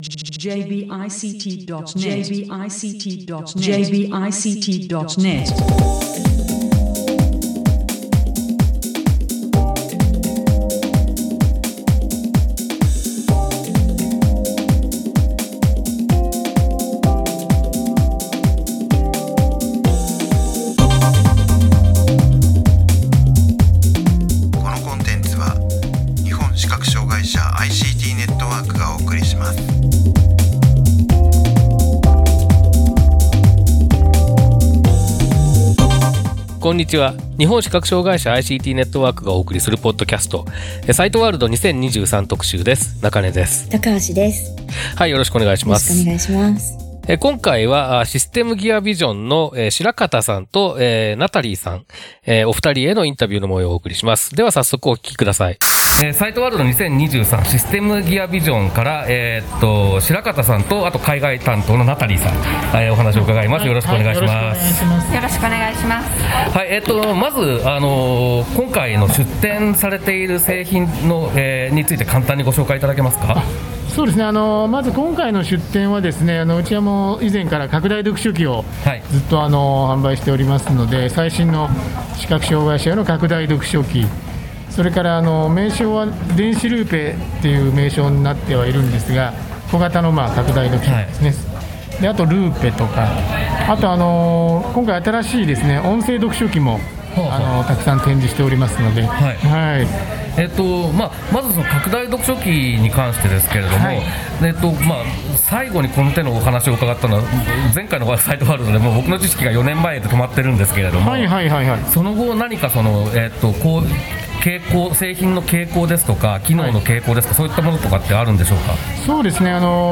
株式会社システムギアビジョンのインタビューをお送りします。最近の拡大読書機関連の動向に加えて、音声読書機「エンジェルビジョンデスクトップリーダー」について伺っています。
サイトワールド2023特集第10回：株式会社システムギアビジョン ファイルのダウンロード: サイトワールド2023特集第10回：株式会社システムギアビジョン 2023年11月1日から3日にかけて、東京都内で開催されたサイトワールド2023での取材の模様をお送りしている、サイトワールド2023特集の第10回です。